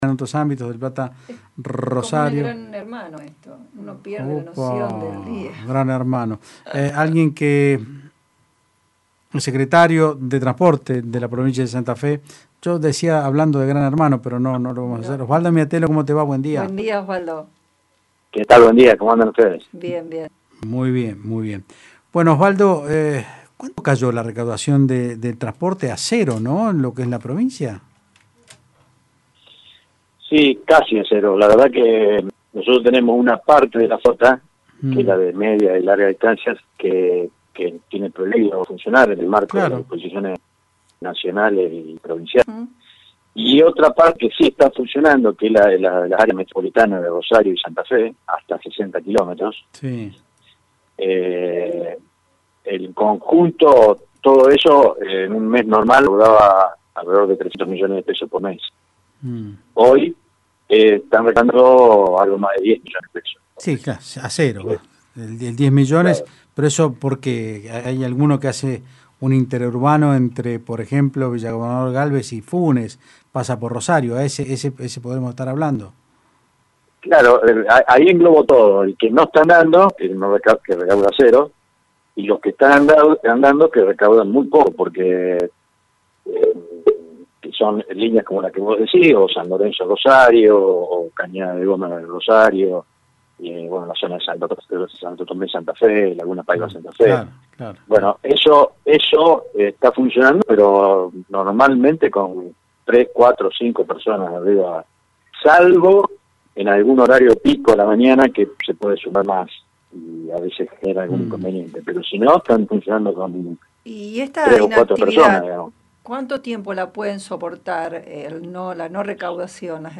El secretario de transporte Osvaldo Miatello dijo en Otros Ámbitos (Del Plata Rosario 93.5) que los servicios interurbanos de media y larga no están funcionando, los únicos son los que cubren las áreas metropolitanas de Rosario y Santa Fe, pero como viaja muy poca gente la recaudación no alcanza para cubrir los gastos de combustible. En ese sentido, el funcionario provincial precisó que en el primer mes de aislamiento el sistema en la provincia dejó de recaudar 290 millones de pesos.